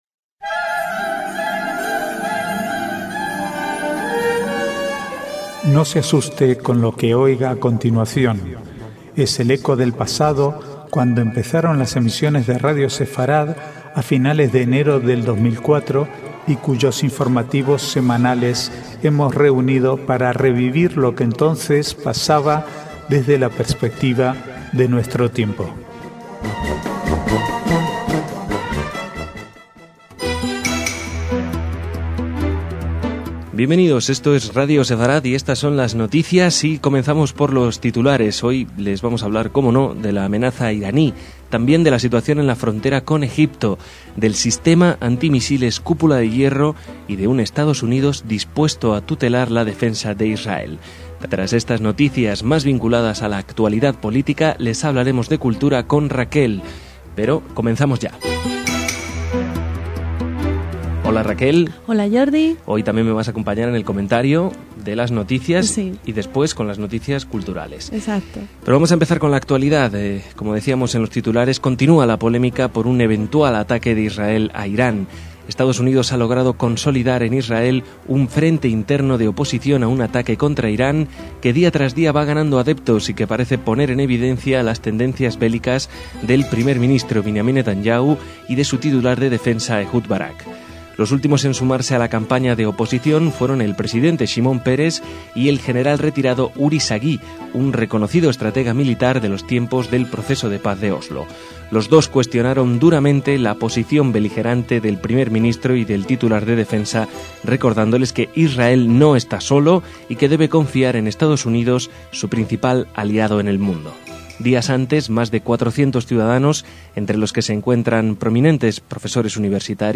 Archivo de noticias del 21 al 24/8/2012